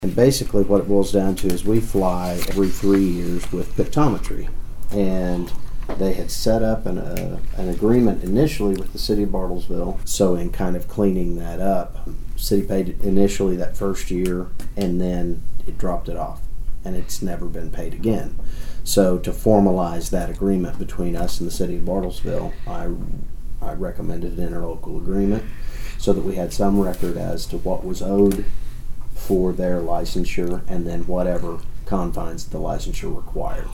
Commissioner Mitch Antle explains.